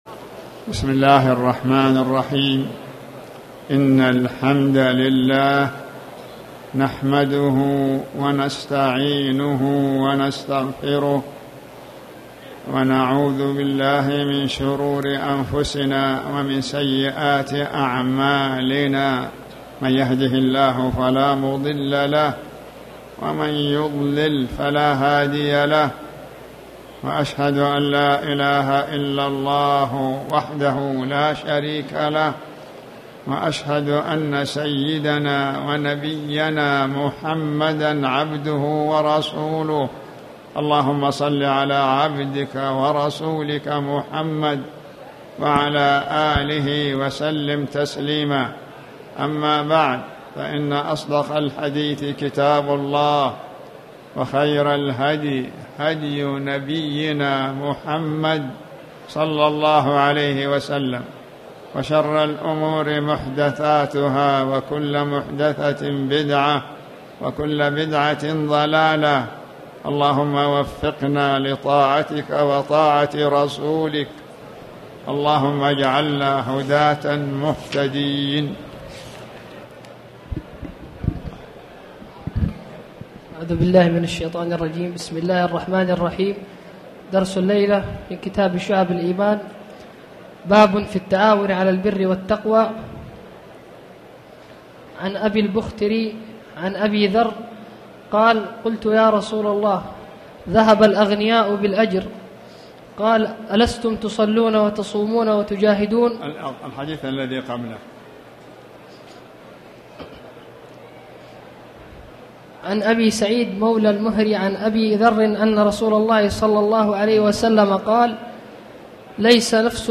تاريخ النشر ٨ شوال ١٤٣٨ هـ المكان: المسجد الحرام الشيخ